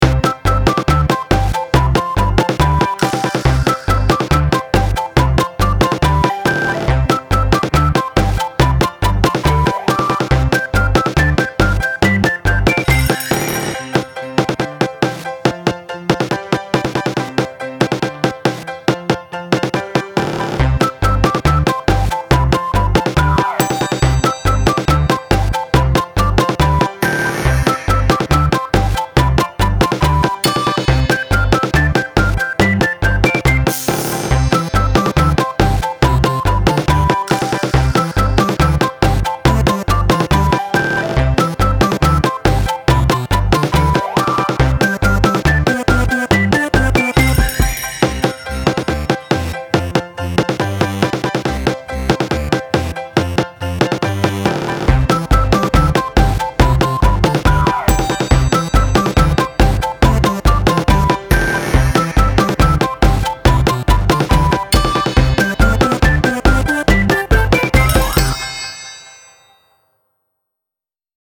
Style Style Chiptune, Soundtrack
Mood Mood Bright, Funny
Featured Featured Drums, Percussion, Piano +2 more
BPM BPM 140